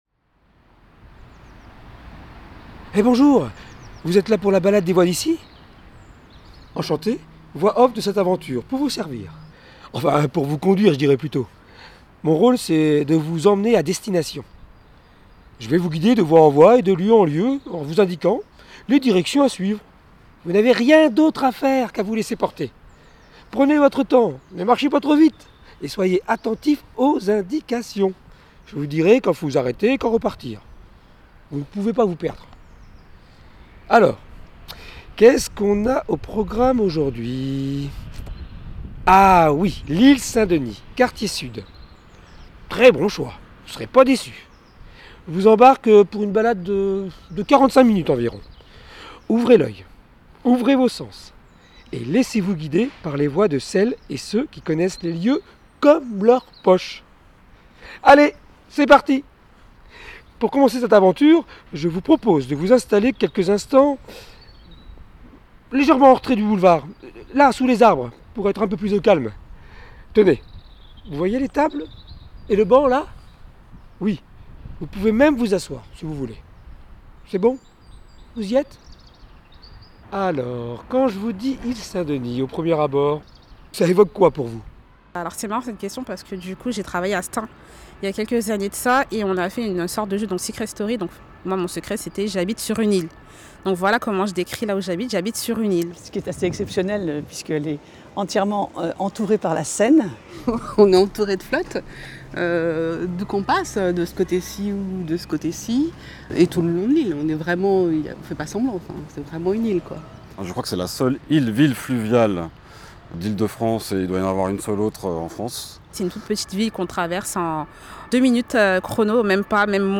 l'audioguide des habitants